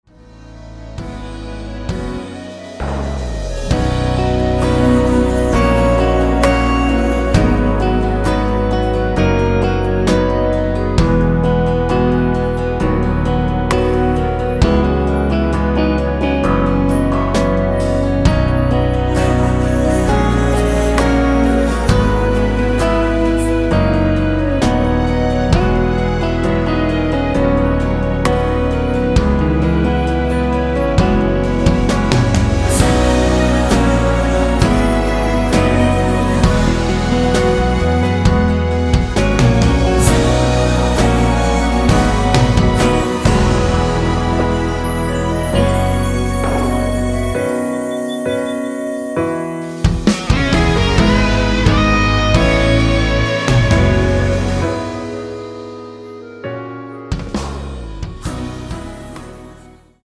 원키에서(-2)내린 멜로디와 코러스 포함된 MR입니다.(미리듣기 확인)
앞부분30초, 뒷부분30초씩 편집해서 올려 드리고 있습니다.
중간에 음이 끈어지고 다시 나오는 이유는